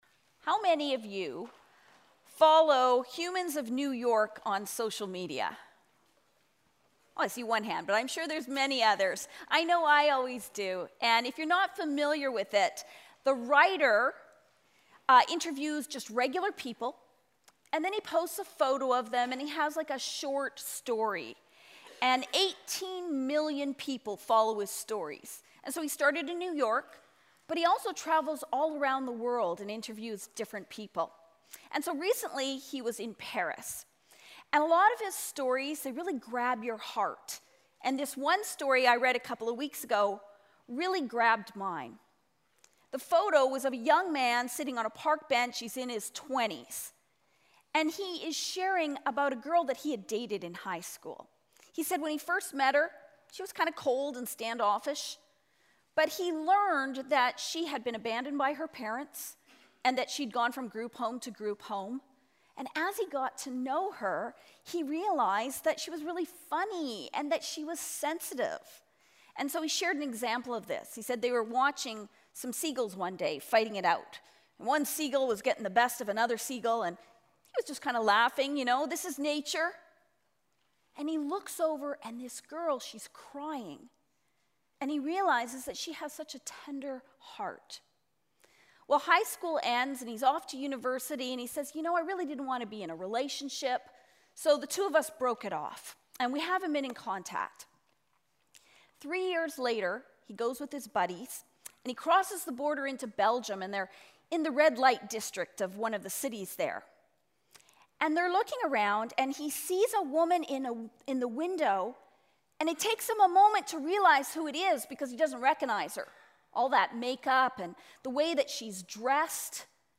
Sermons | Calvary Baptist Church